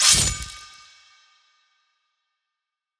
HIT 11 .wav